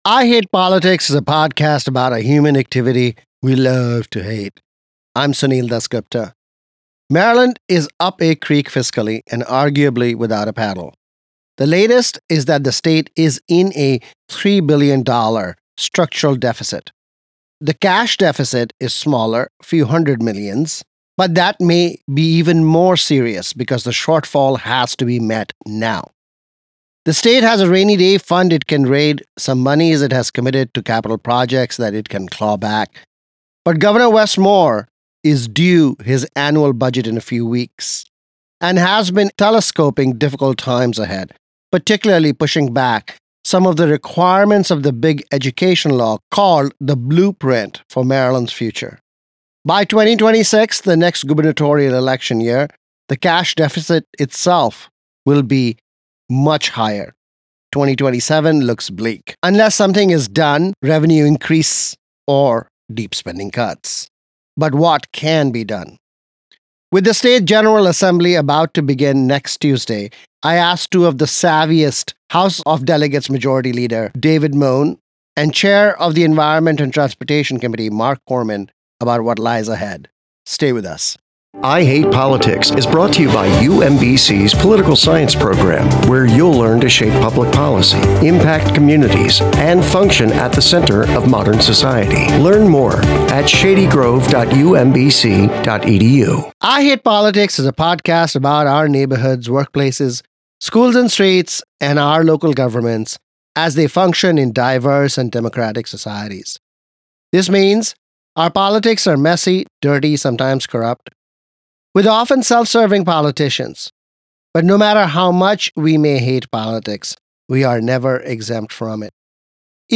Newly in public domain music by George Gershwin and Nacio Herb Brown.